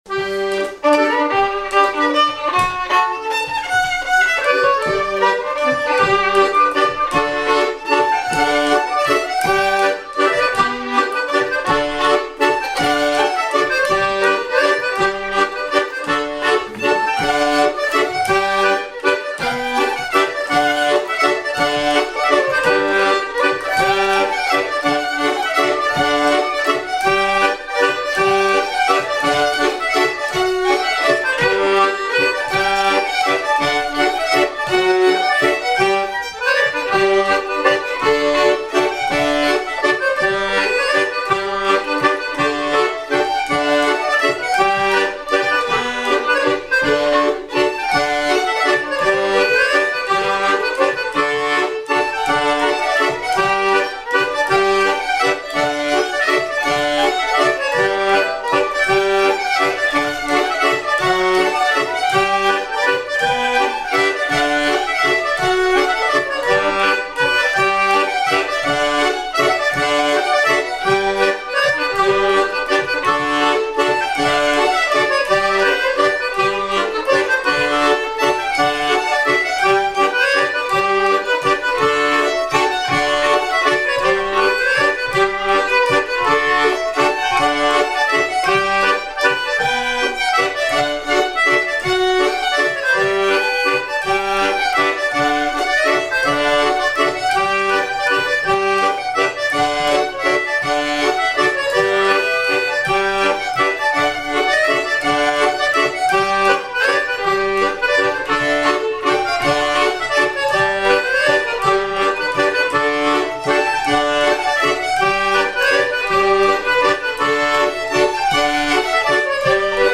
Miquelon-Langlade
Chants brefs - A danser Résumé : Quand je vois porter des lunettes à des gens qui n'en ont pas besoin.
danse : mazurka
Répertoire de bal au violon et accordéon